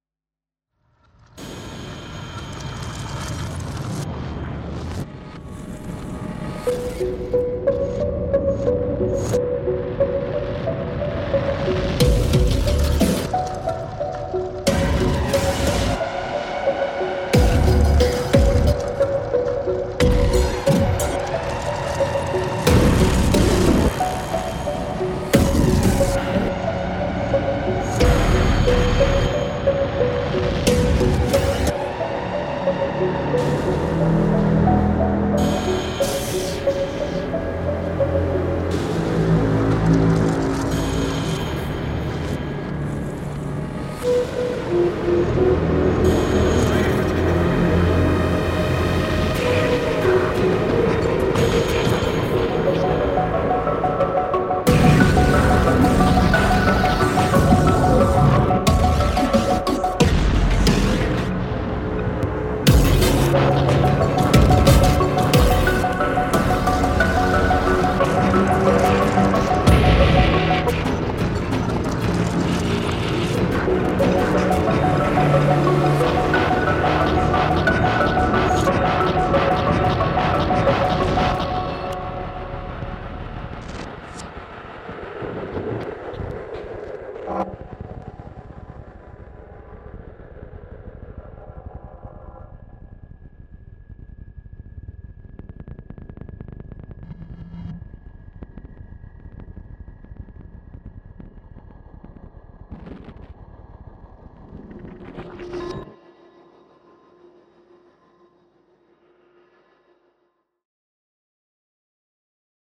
Hybrid track for horror and mystery.
Hybrid track for horror, mystery and podcasts.